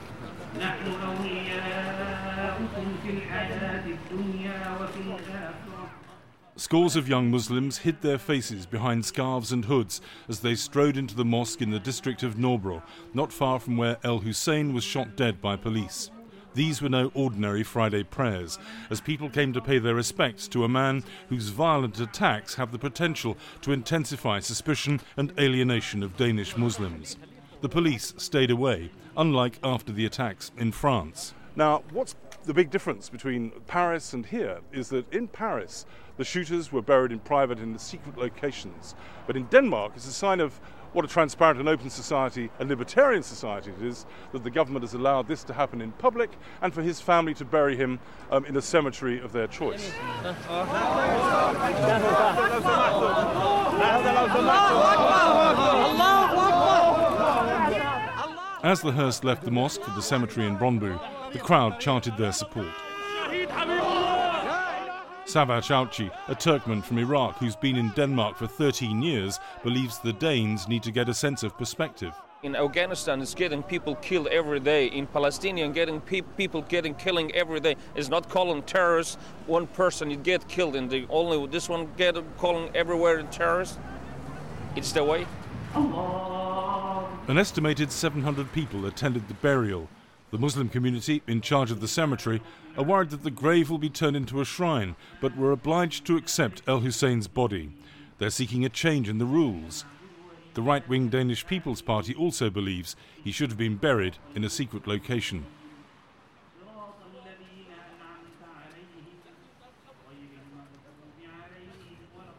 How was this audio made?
reports from a mosque in Copenhagen